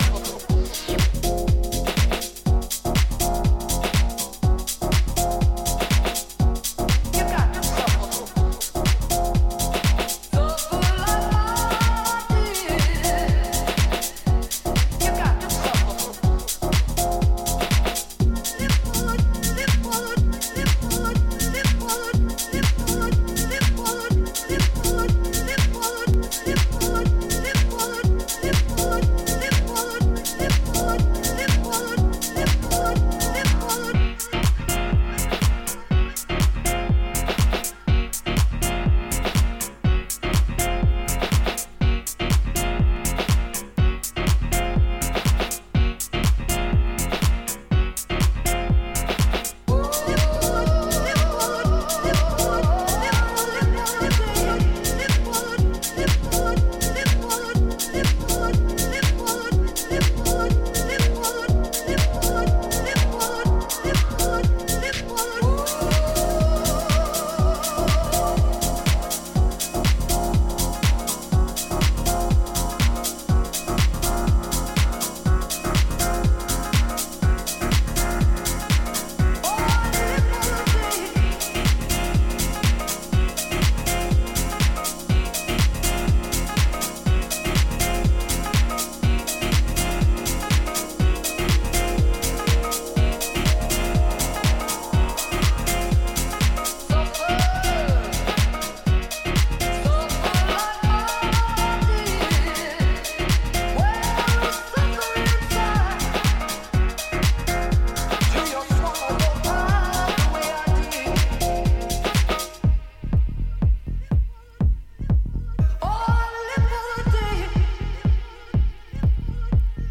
軽快で色艶のあるピアノ・リフやソウルフルなヴォーカル・サンプルなどを用いながらモダンでウォームなハウスを展開していった、